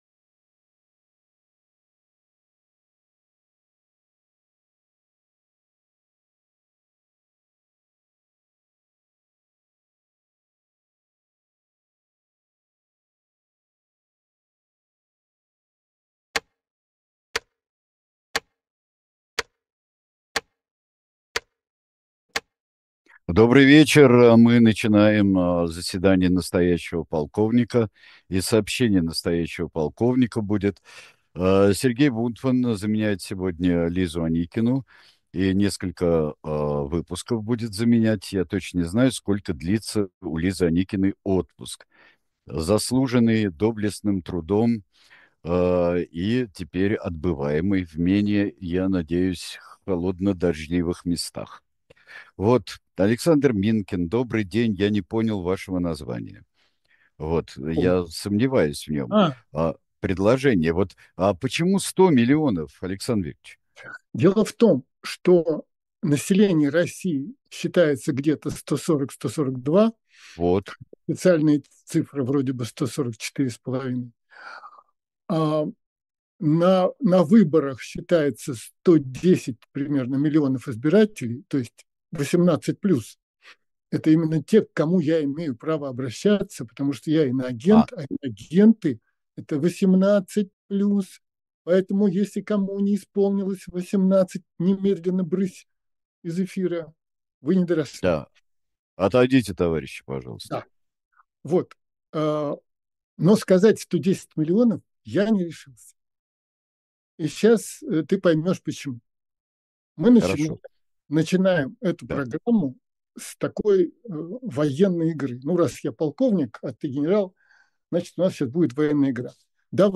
Программу ведут Александр Минкин и Сергей Бунтман